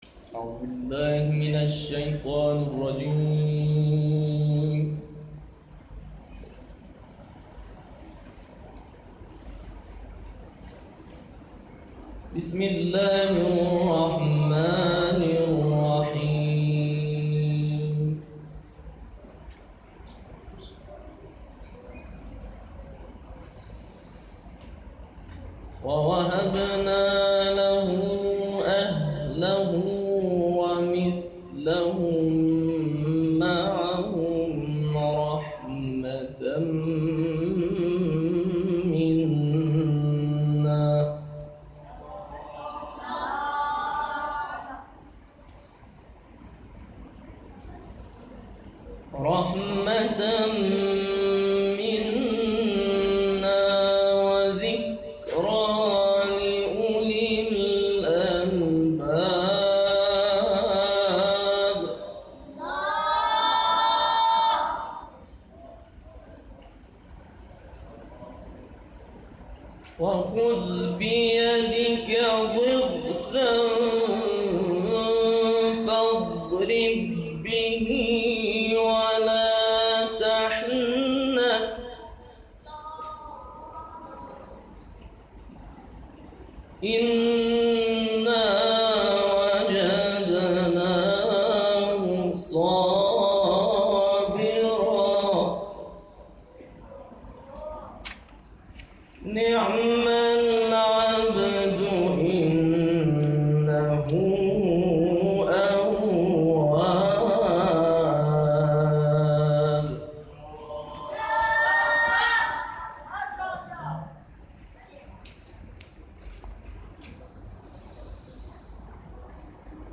صوت تلاوت